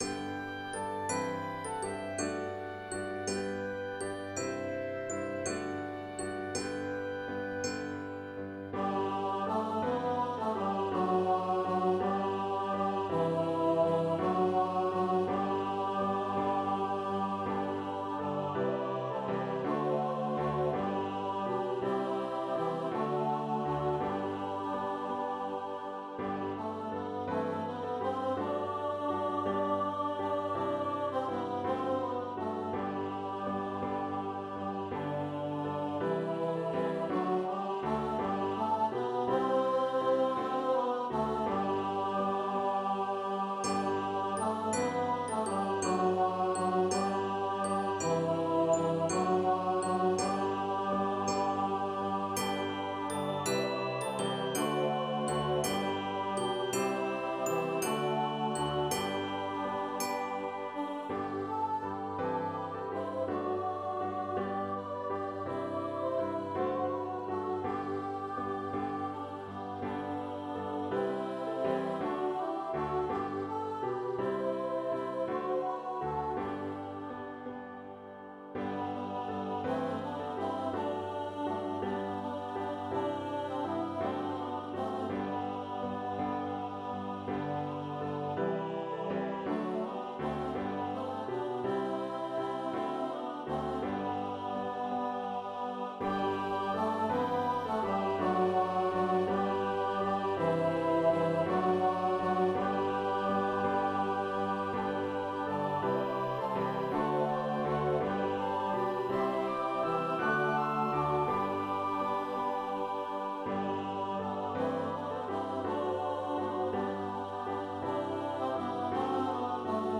The second recording is what it would be like if it were sung today, with flutes and handbells added to the score.
When you do not have a choir, you make do with what you can get from a computer program.
ALL-THE-ENDS-OF-THE-EARTH-FULL-SCORE.mp3